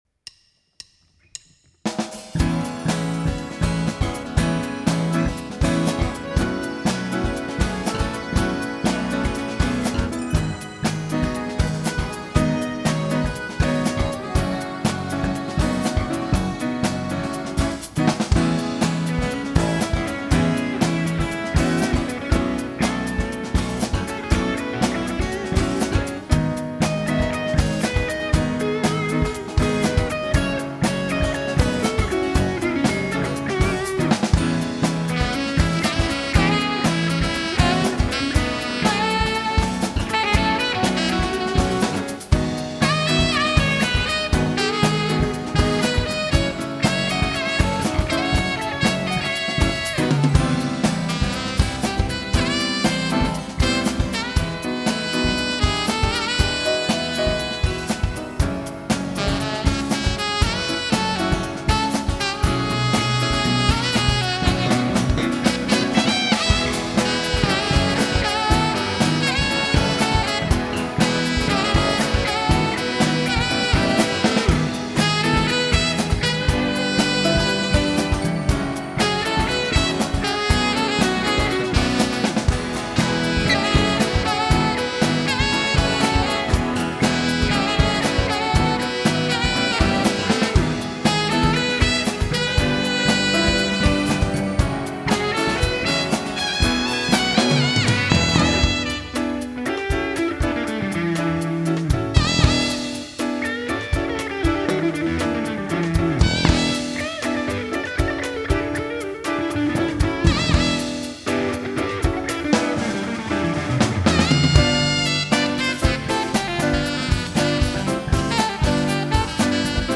르베이르에 해리슨이면 소리가 더 파음되면서 티스에 살짝 어울리지않을수도 있다는 생각이.